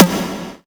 VEC3 Percussion 065.wav